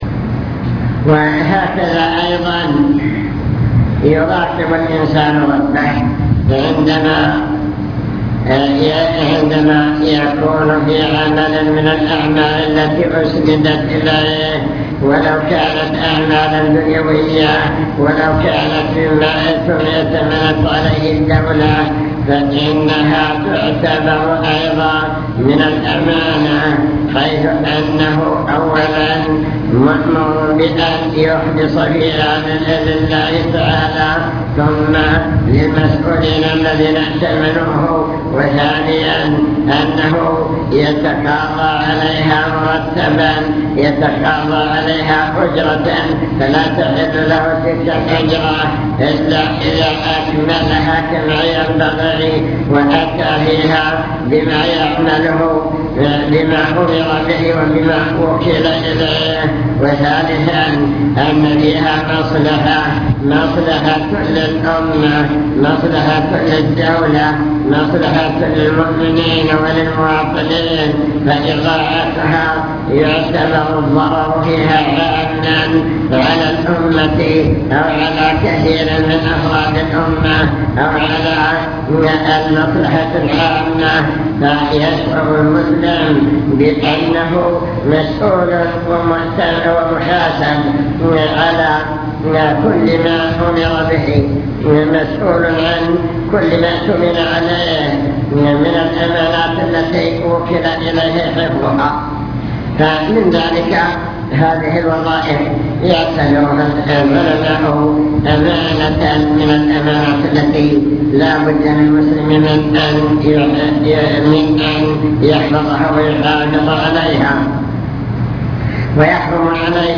المكتبة الصوتية  تسجيلات - محاضرات ودروس  أطب مطعمك